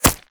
bullet_impact_rock_03.wav